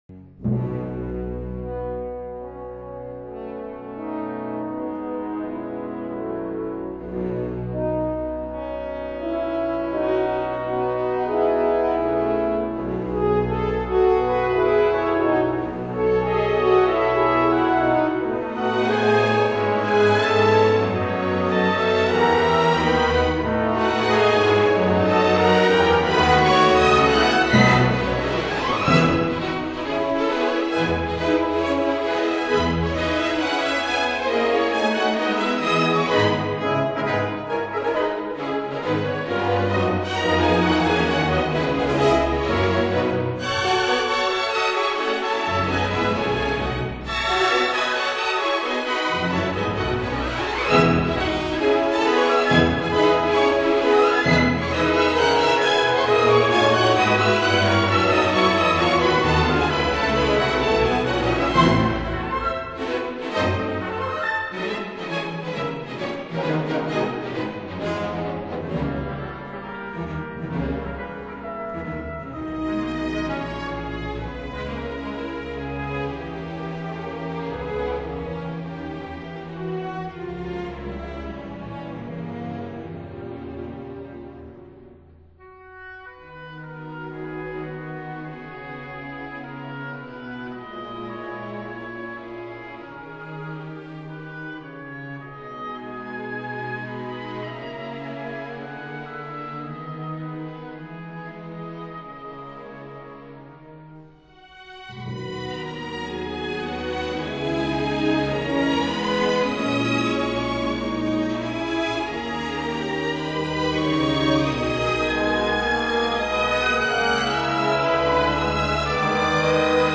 Symphony in E minor
incidental music